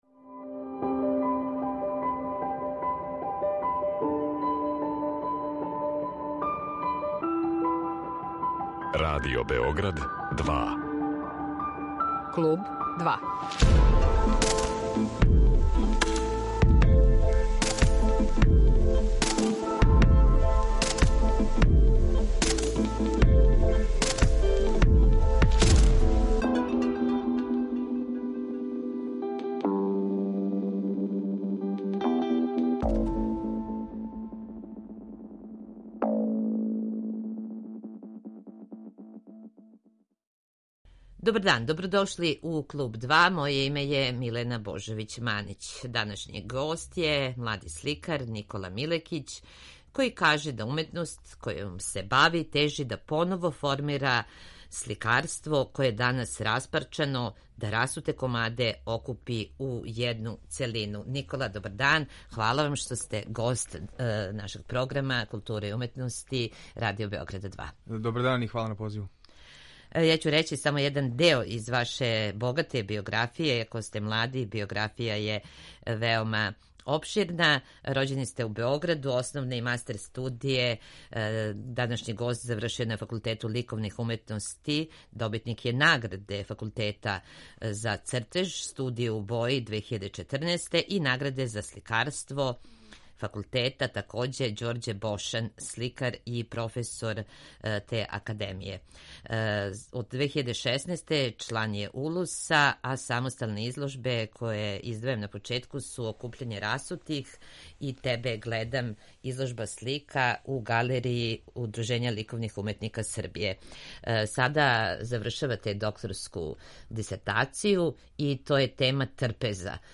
Гост емисије је сликар